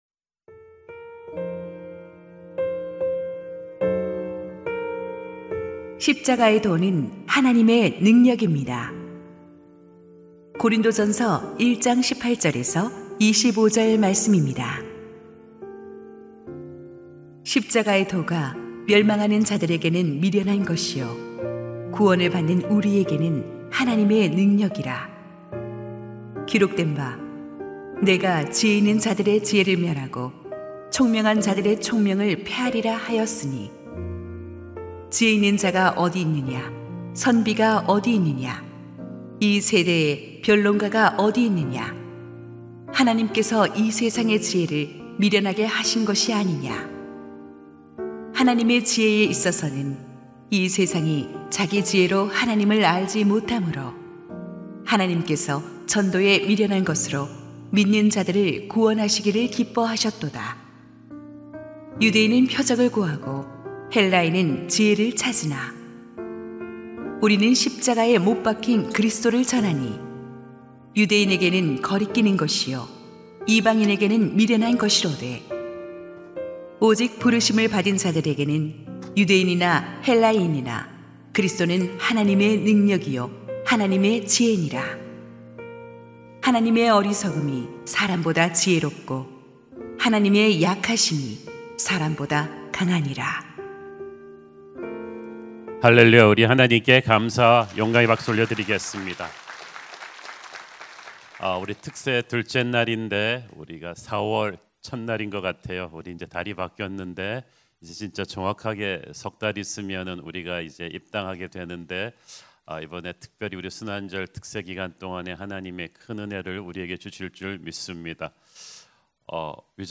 2026-04-01 고난주간 특별새벽기도회
> 설교